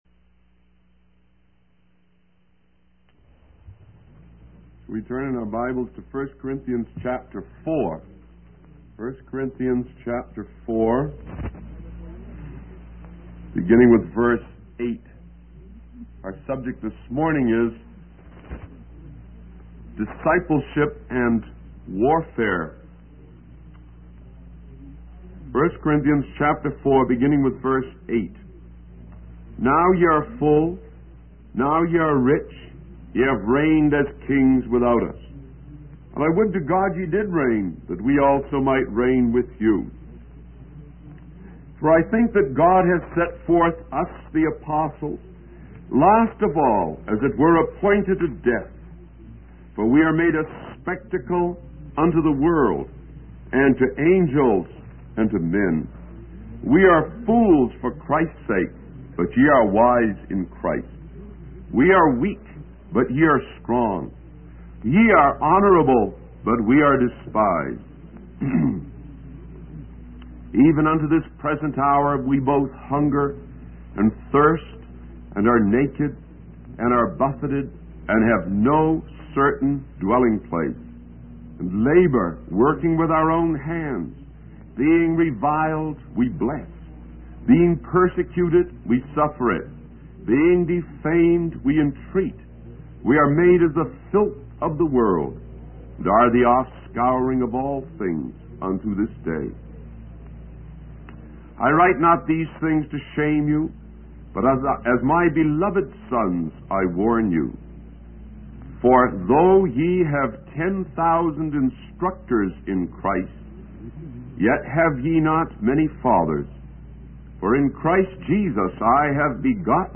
In this sermon, the speaker focuses on the topics of discipleship and warfare.